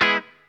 GUIT_1.WAV